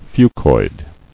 [fu·coid]